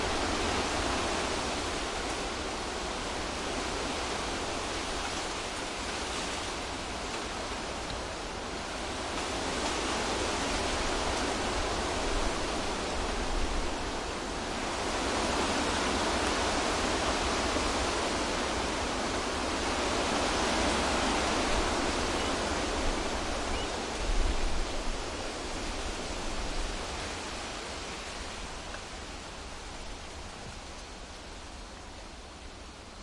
马洛卡 " 风强劲的中等树木，树枝折断的夜晚，轻微的回声覆盖在树下后院的帐篷周围的声音+。
描述：风强中等树木绿叶枝快照夜间轻微的回声覆盖声音在后院的树木附近的帐篷+远处的海鸥附近